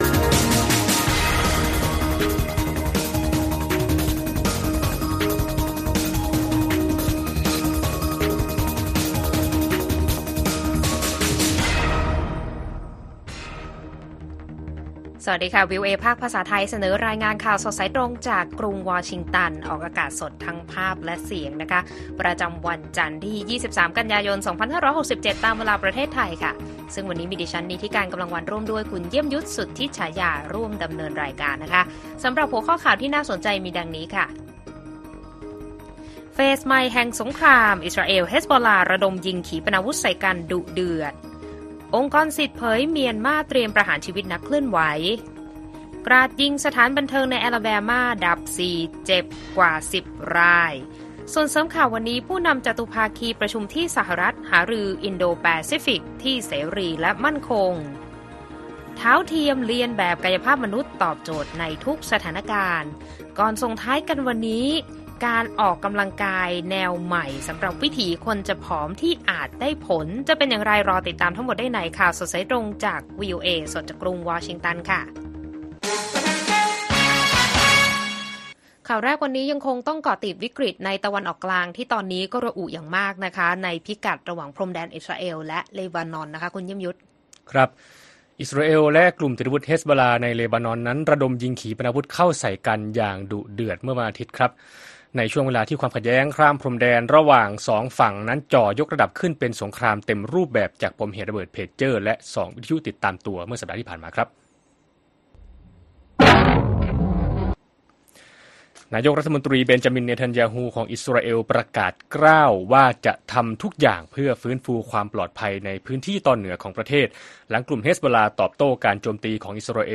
ข่าวสดสายตรงจากวีโอเอไทย จันทร์ ที่ 23 มิ.ย. 67